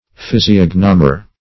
Meaning of physiognomer. physiognomer synonyms, pronunciation, spelling and more from Free Dictionary.
physiognomer.mp3